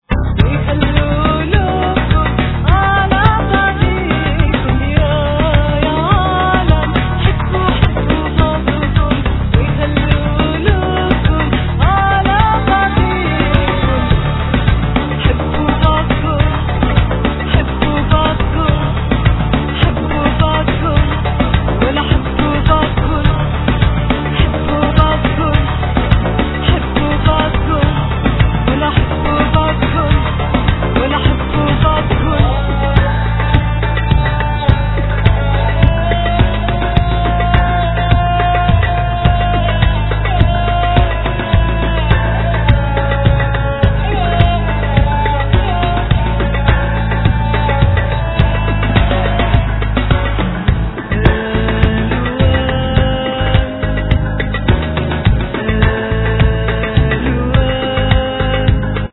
Vocal
Arrangements, Samples, Keys, Guitar, Bass, Saz, Percussions
Drums
Tabla, Disgeridoo, Percussions, Aliquot singing